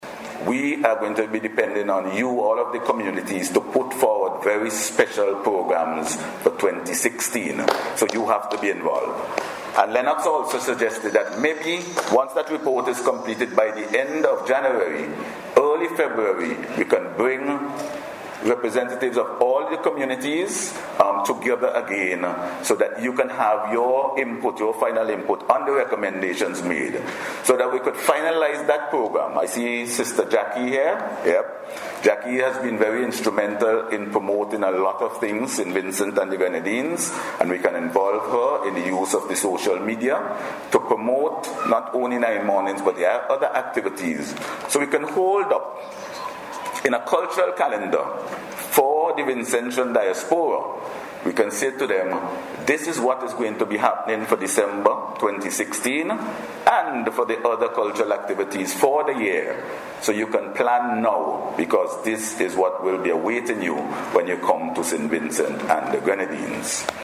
Word of this came from Minister of Culture Cecil McKie as he addressed the Nine Mornings Awards Ceremony on Saturday at the Memorial Hall.